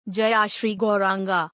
Aditi-voice_Chanting_Gauranga.mp3